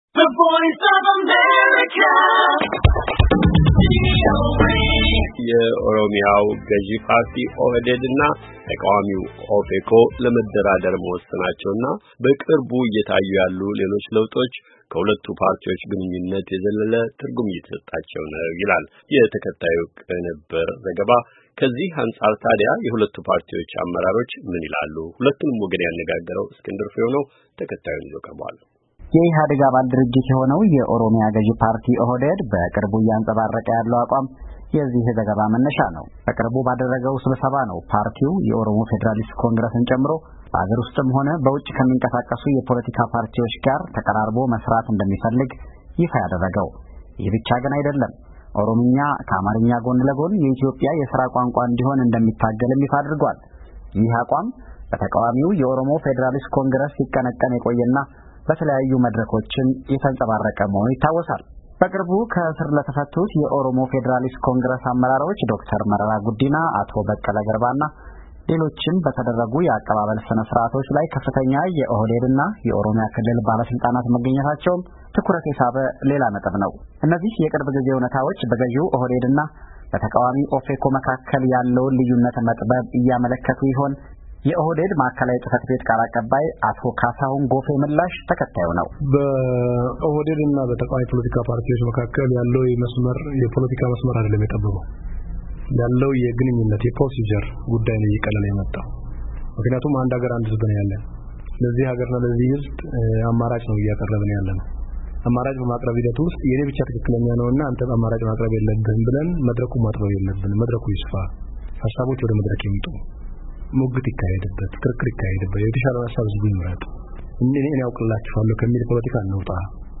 ከኦህዴድ እና ኦፌኮ አመራሮች ጋር የተደረገ ውይይት